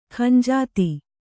♪ khanjāti